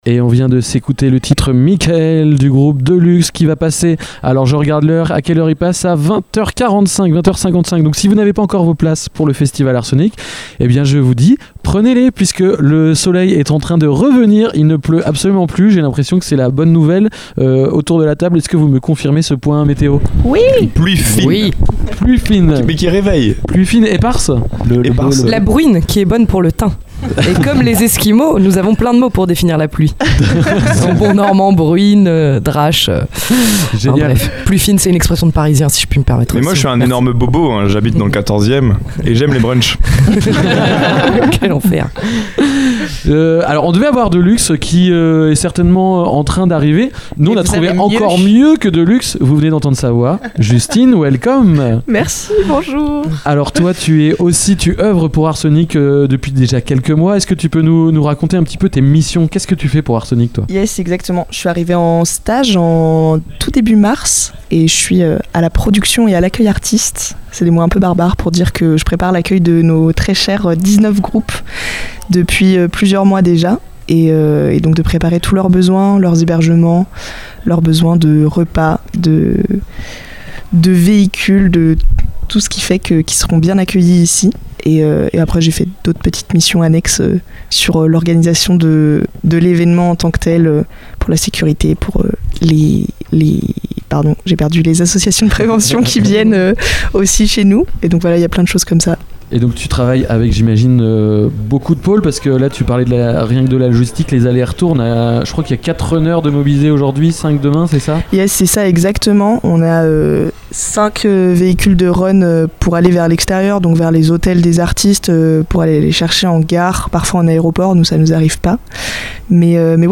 Dans cette interview réalisée dans le cadre de l’émission spéciale enregistrée au festival Art Sonic à Briouze, les radios de l’Amusicale — Ouest Track, Station B, PULSE, Kollectiv’, 666, Radio Sud Manche, Radio Coup de Foudre, Radar, Phénix, Radio Campus Rouen et TST Radio — sont parties à la rencontre des artistes qui font vibrer le festival.